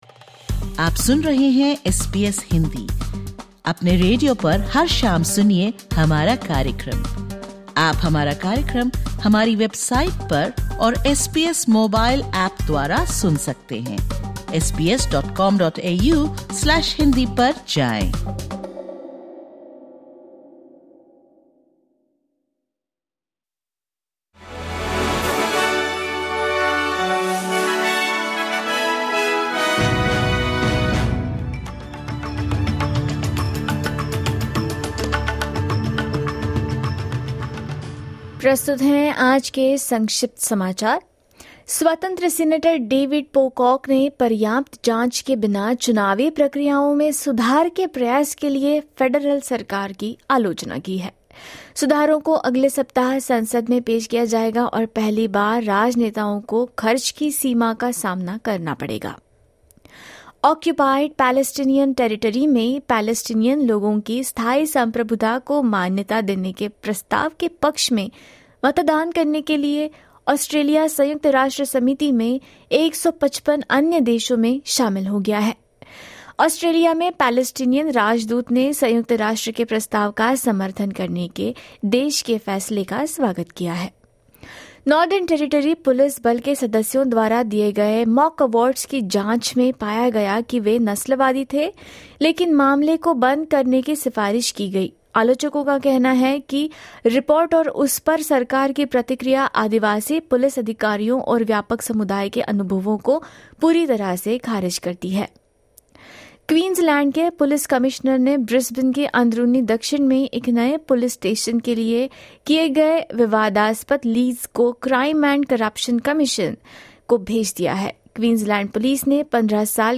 Listen to the top News from Australia in Hindi.